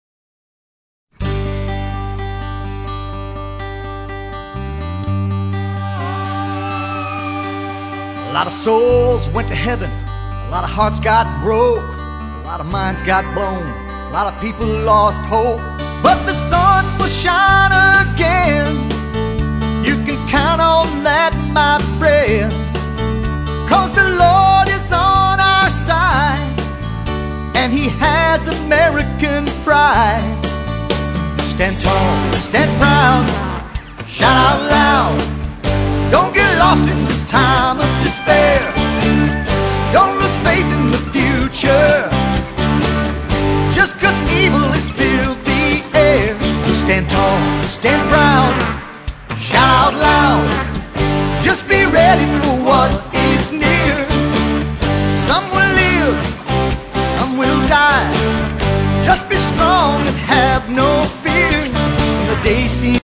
mp3 / Country